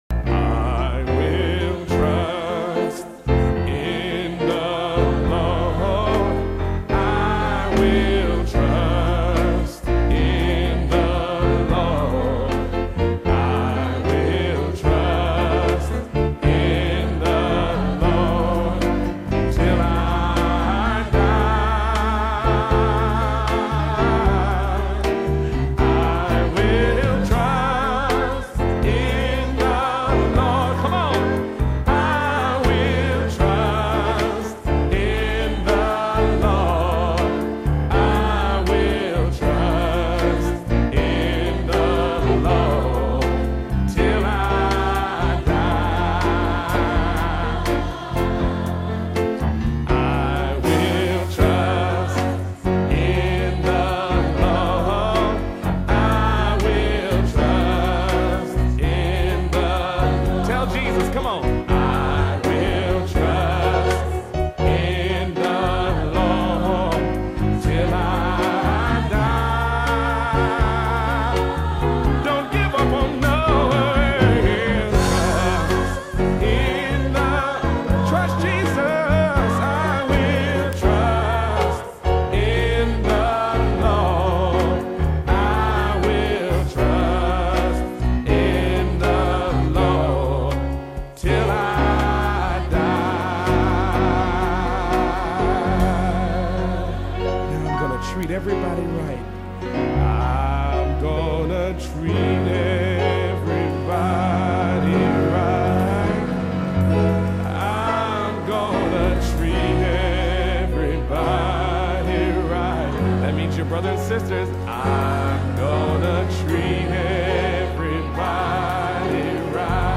VOCALIST
A Classic Gospel Compilation